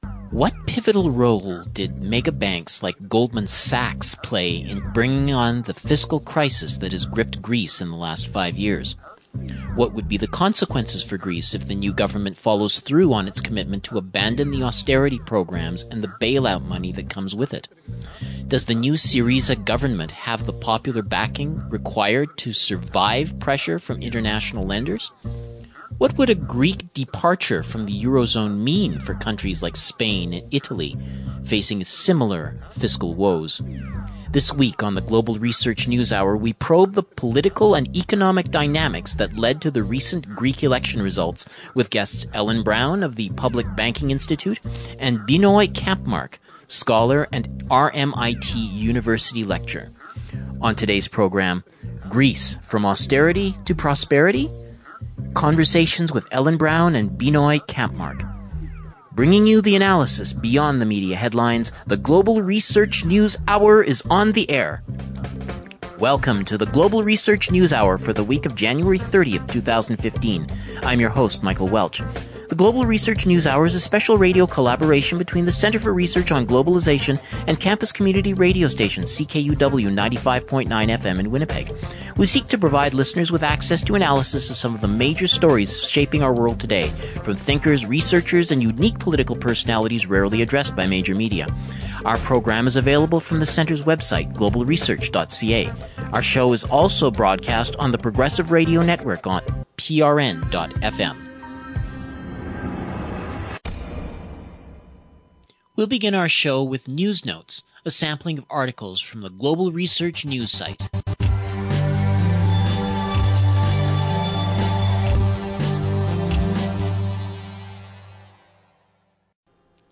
community radio exchange